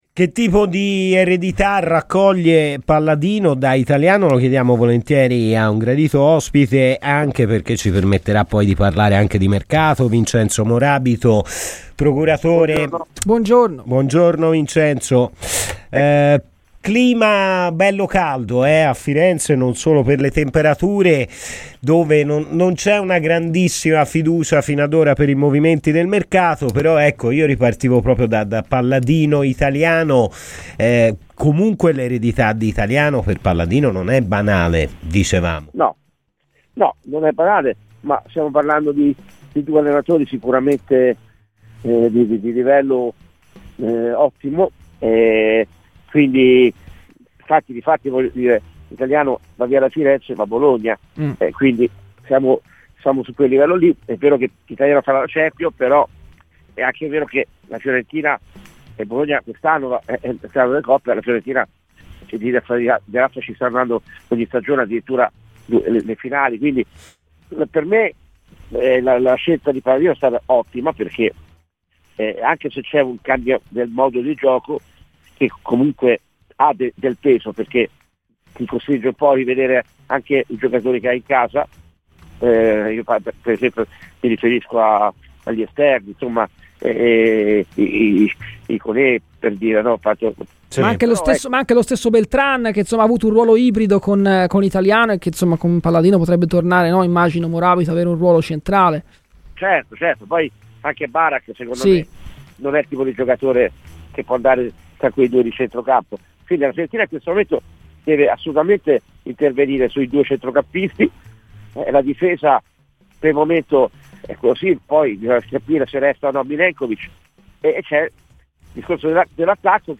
PER ASCOLTARE L'INTERVISTA COMPLETA ASCOLTA IL PODCAST!